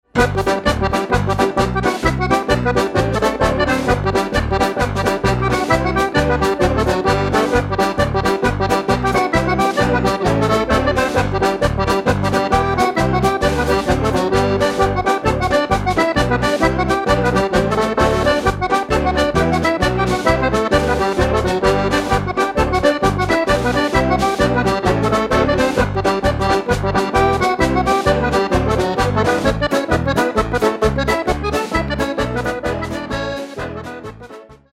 Irish Jigs